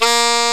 Index of /m8-backup/M8/Samples/Fairlight CMI/IIX/REEDS
BARISAX1.WAV